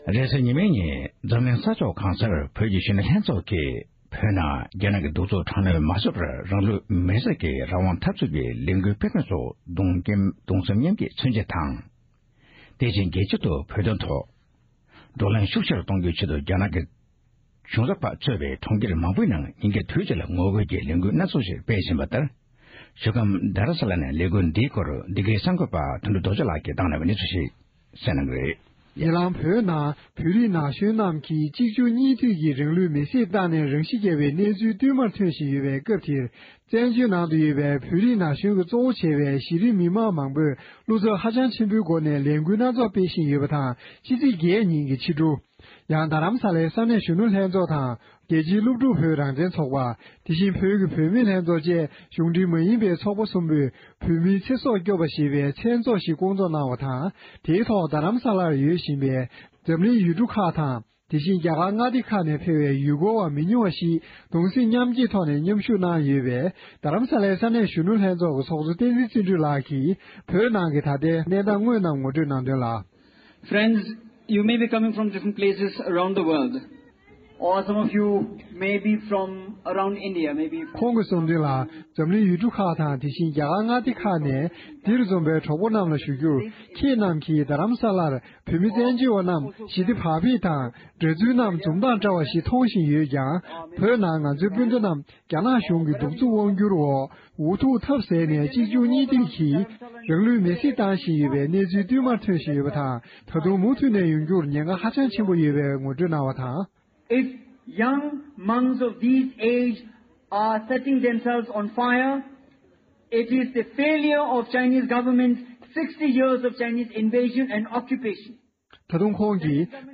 གནས་ཚུལ་ཕྱོགས་སྒྲིག་ཞུས་པར་གསན་རོགས༎